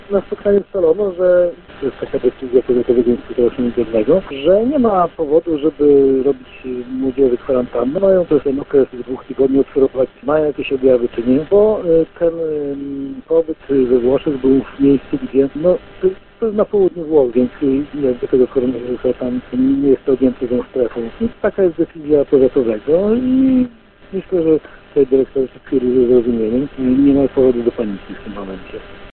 – Reagujemy adekwatnie do sytuacji – zapewnia w rozmowie z Radiem 5 starosta powiatu ełckiego Marek Chojnowski, stojący na czele Powiatowego Centrum Zarządzania Kryzysowego.